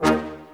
BRASSHTD#3.wav